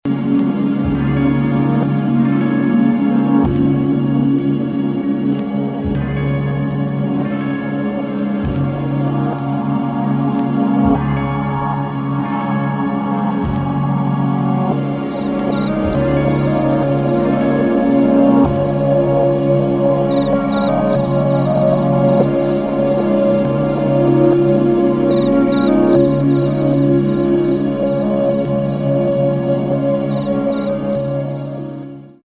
[ TECHNO | AMBIENT ]